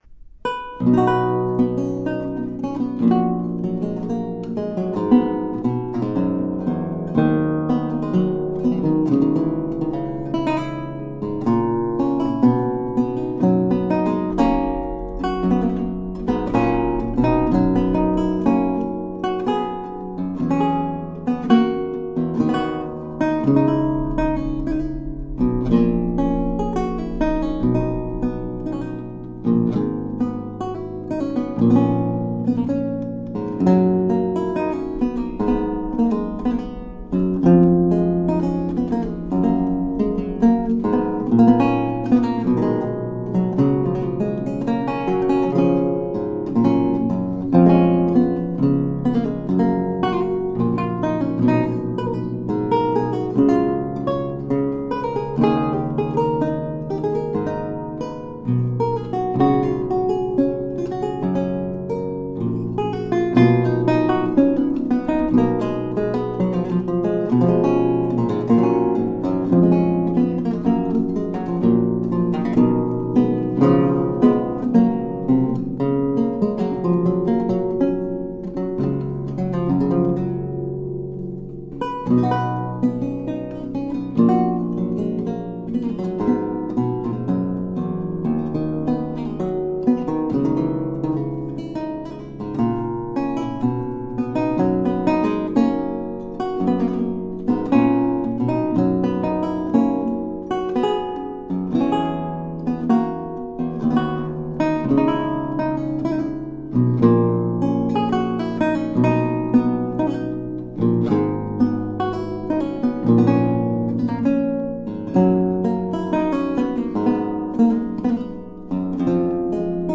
A marvelous classical spiral of lute sounds.
Classical, Baroque, Instrumental Classical
Lute